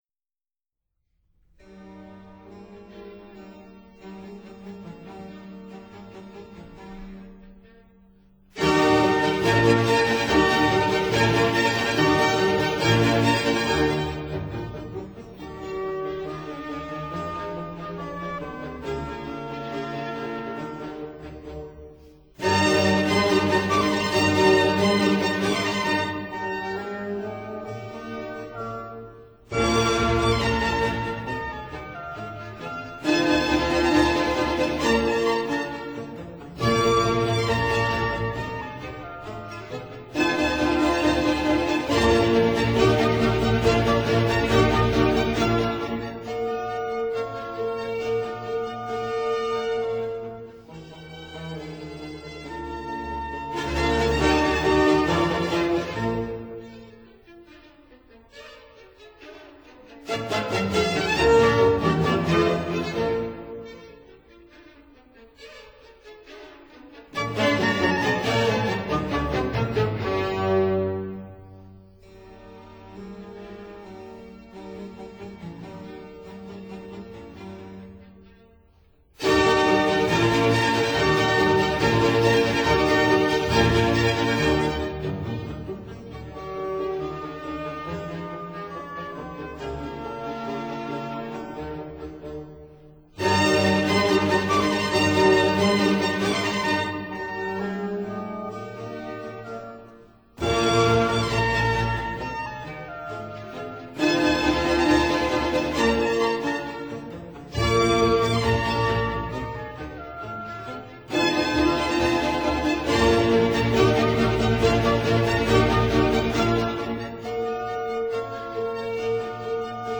Symphony in C minor, G. 519 (Op. 41)
(Period Instruments)